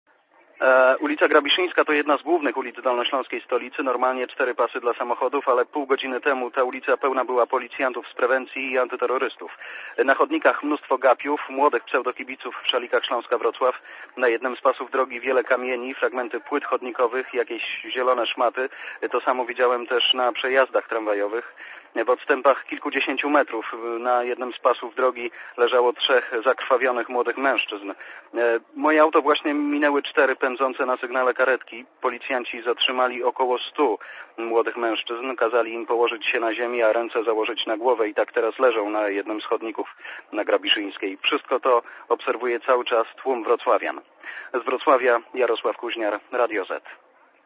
Na miejscu zdarzenia był dziennikarz Radia Zet (184Kb)